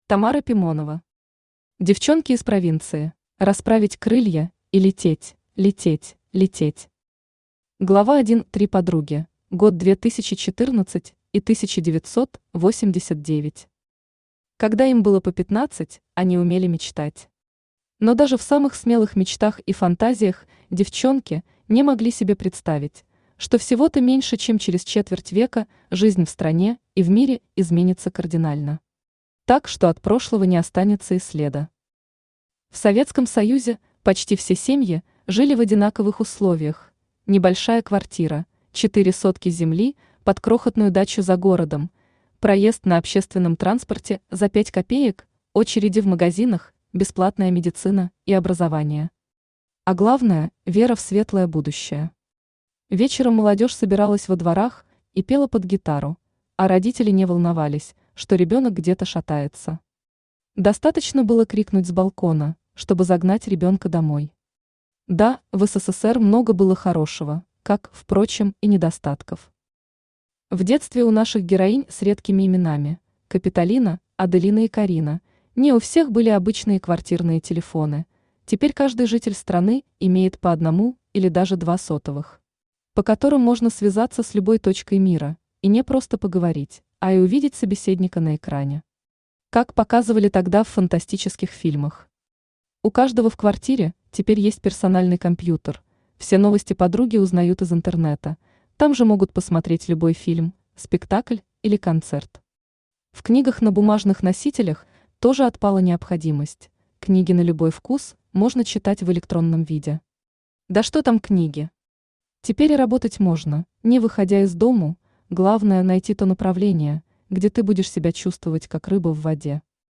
Аудиокнига Девчонки из провинции | Библиотека аудиокниг
Aудиокнига Девчонки из провинции Автор Тамара Ивановна Пимонова Читает аудиокнигу Авточтец ЛитРес.